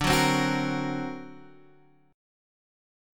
D+7 chord